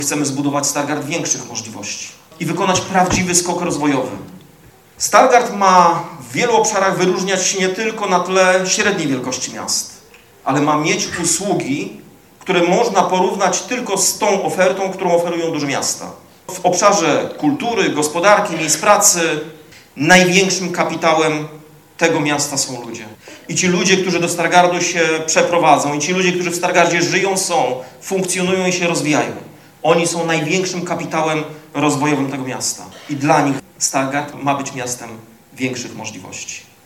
W Stargardzkim Centrum Kultury odbyła się konwencja komitetu wyborczego obecnego prezydenta miasta, Rafała Zająca.